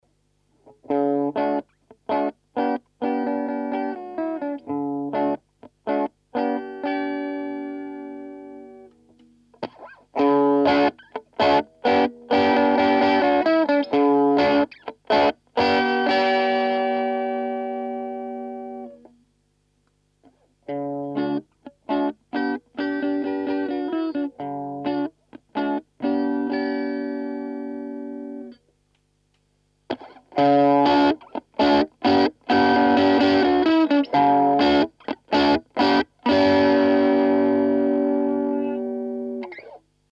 Here's a quick clip with and without the treble booster. It's 4 bits 2 pickups clean then through treble booster 1 pickup clean then through treble booster. My only concern is that it sounds terrible going into a completely clean channel; Held chords kinda sound like the batteries are dying in an old radio.
Other than that it sounds pretty good, I think.